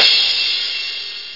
crash1.mp3